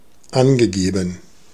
Ääntäminen
Synonyymit set Ääntäminen US Haettu sana löytyi näillä lähdekielillä: englanti Käännös Ääninäyte Adjektiivit 1. angegeben 2. aufgeführt Specified on sanan specify partisiipin perfekti.